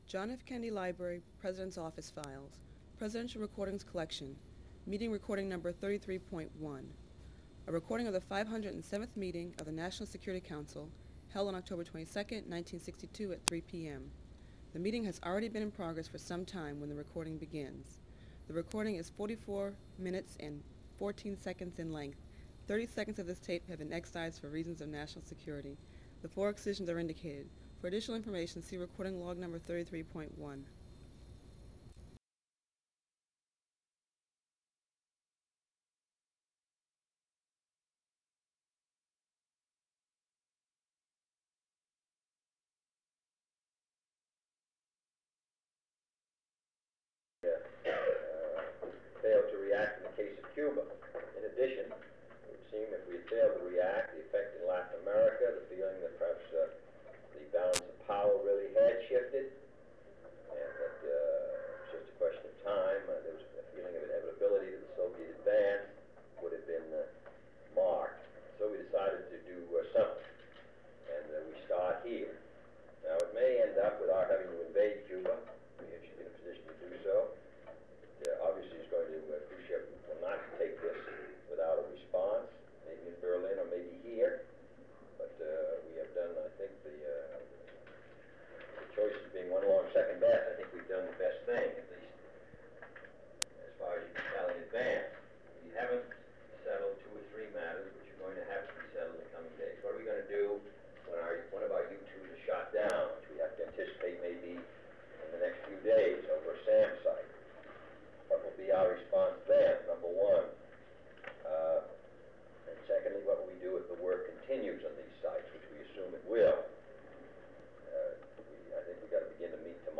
Secret White House Tapes | John F. Kennedy Presidency National Security Council Meeting on the Cuban Missile Crisis Rewind 10 seconds Play/Pause Fast-forward 10 seconds 0:00 Download audio Previous Meetings: Tape 121/A57.